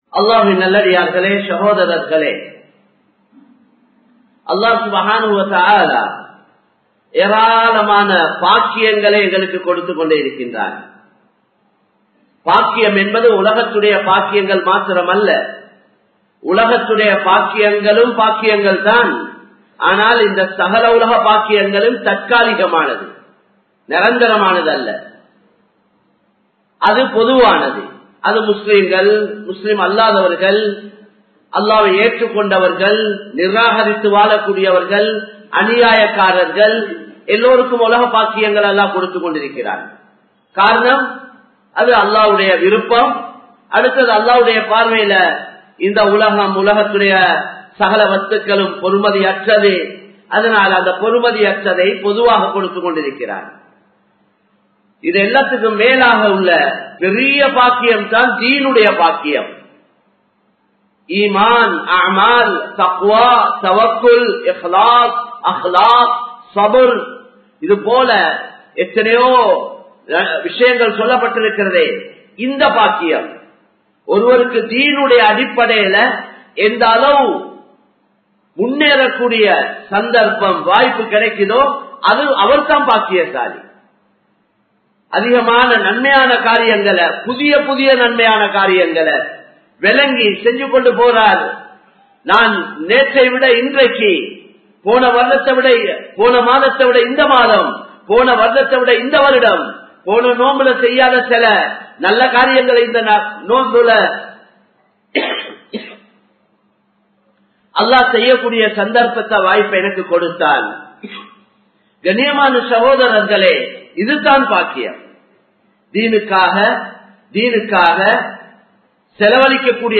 செல்வமும் ஸகாத்தும் | Audio Bayans | All Ceylon Muslim Youth Community | Addalaichenai
Samman Kottu Jumua Masjith (Red Masjith)